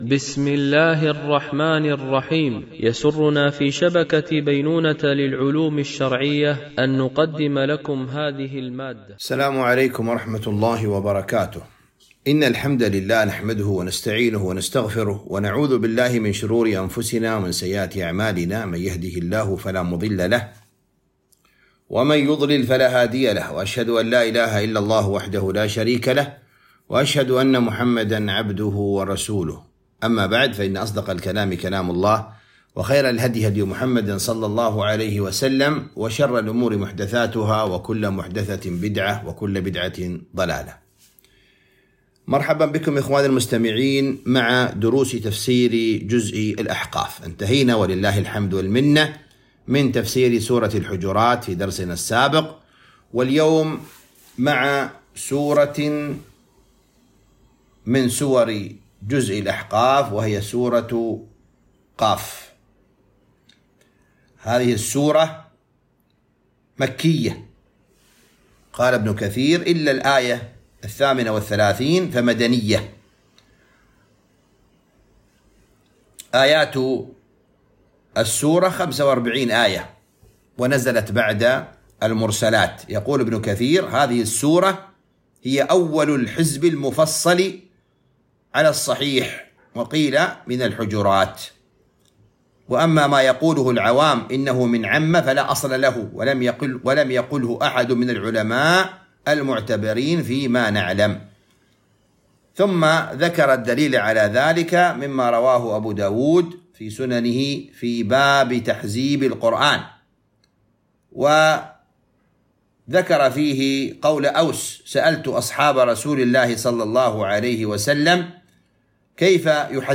تفسير جزء الذاريات والأحقاف ـ الدرس 16 ( سورة ق )